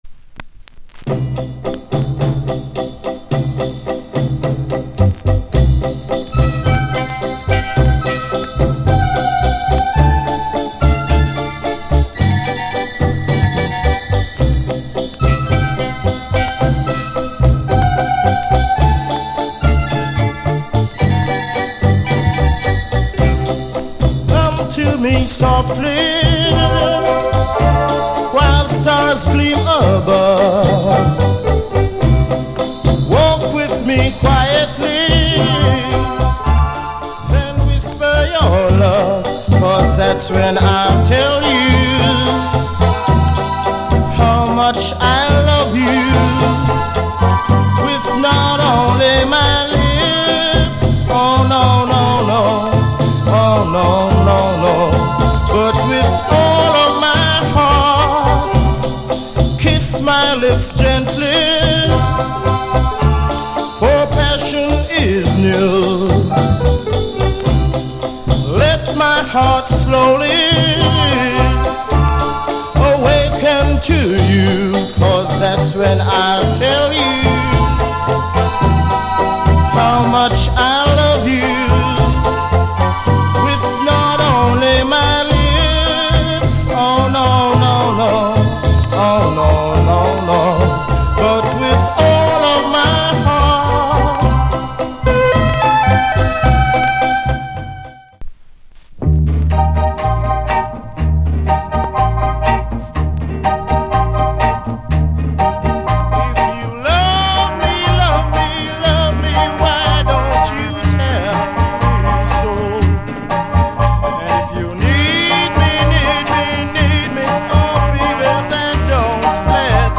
* great. sweetest Jamaican ballad.